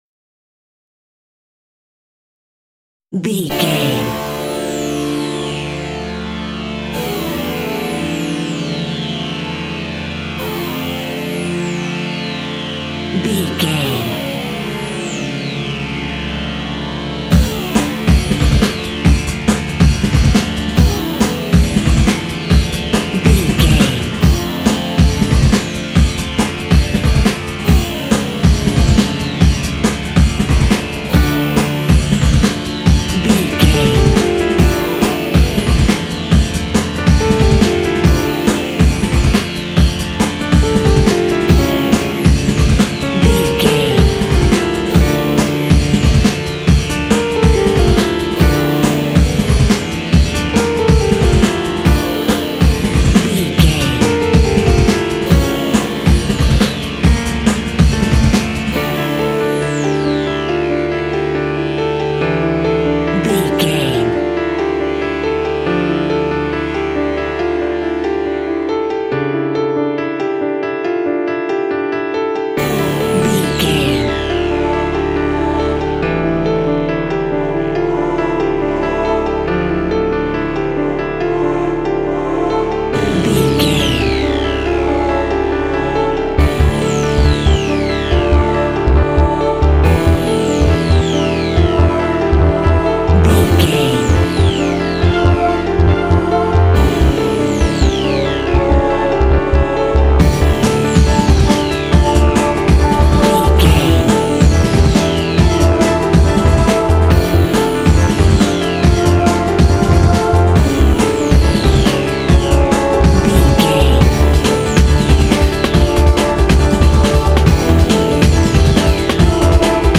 In-crescendo
Thriller
Aeolian/Minor
Fast
tension
ominous
suspense
eerie
synthesizer
drums
piano
strings
ambience
pads